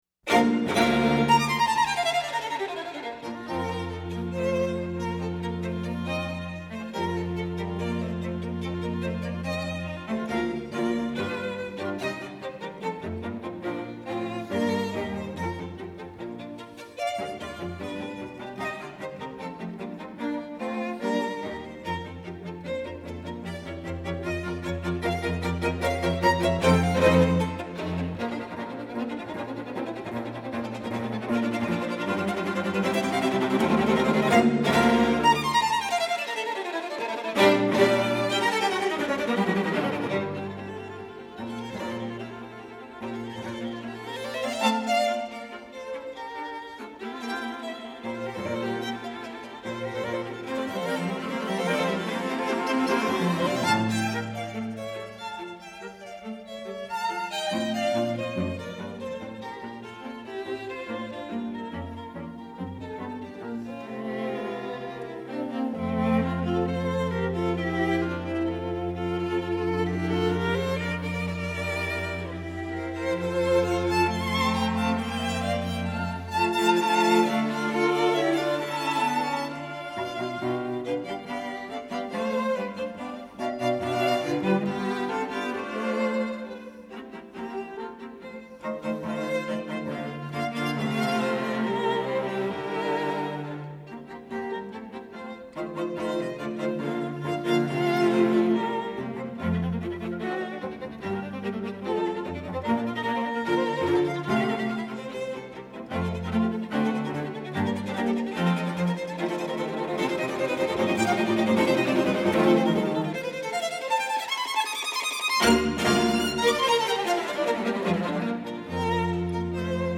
Listen to historic chamber music recordings online as heard at Vermont's Marlboro Music Festival, classical music's most coveted retreat since 1951.
String Quintet in B-flat Major, Op. 87
08_mendelssohn_string_quintet_in_b.mp3